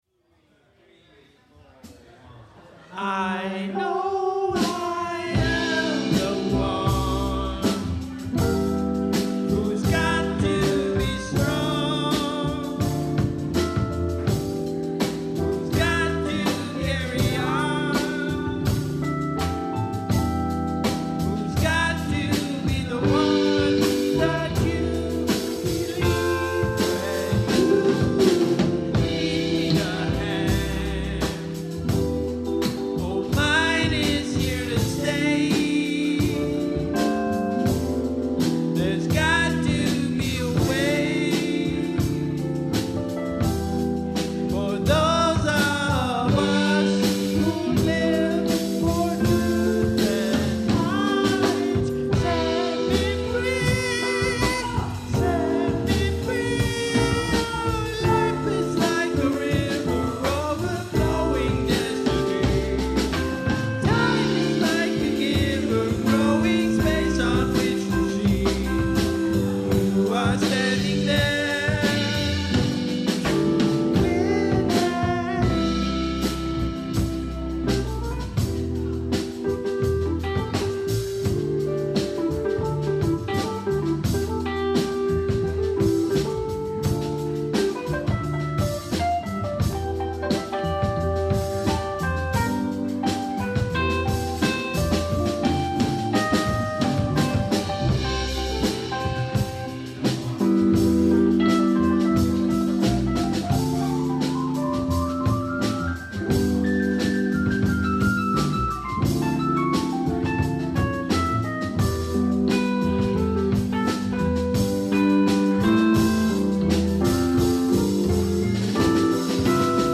Vocals, Trumpet, Flugelhorn, Rhythm Guitar.
Lead Guitar
Keyboard
Bass
Drums